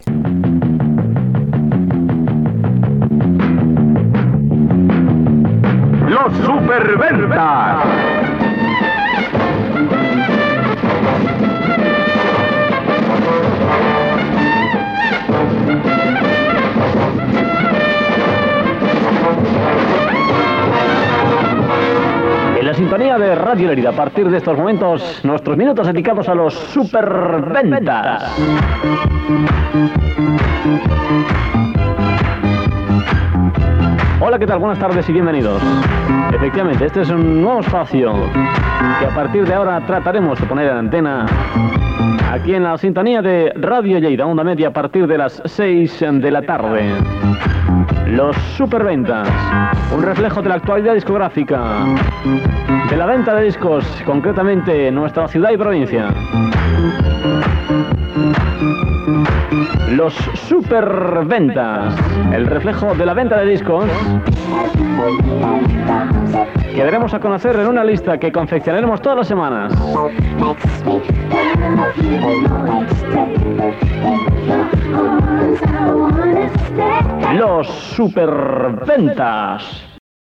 Indicatiu del programa, presentació
Musical